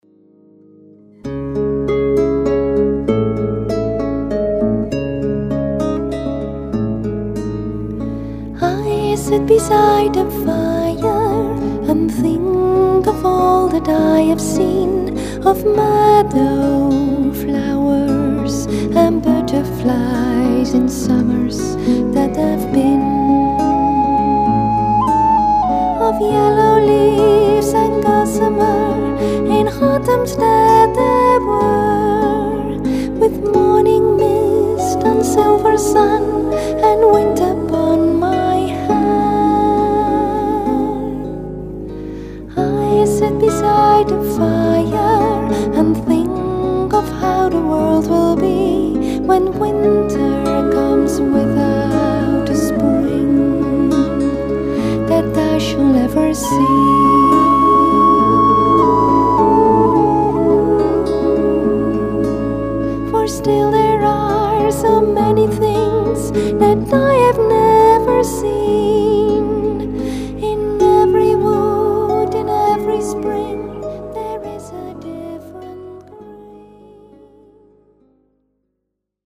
Elvish band
two celtic female voices singing